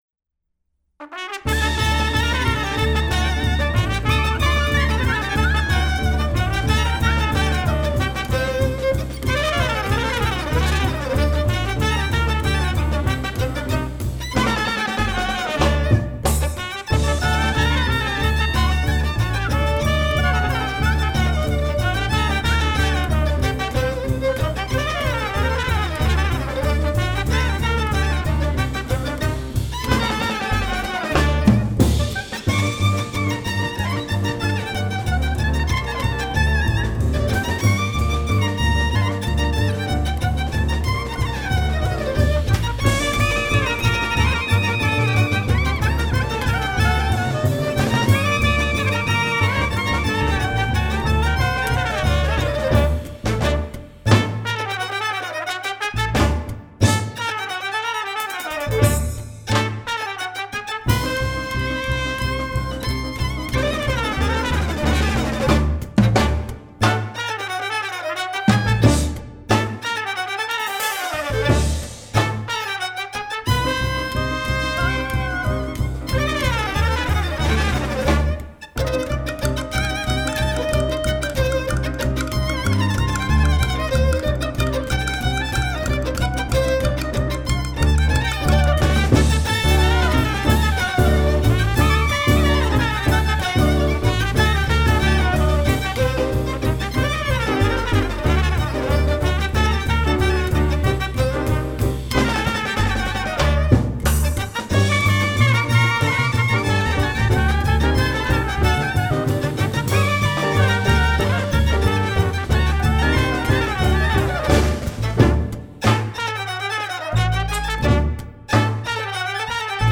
Trumpet
Drums
Woodwinds
Bass
Piano
We recently recorded a live concert in St. Cloud, Minnesota.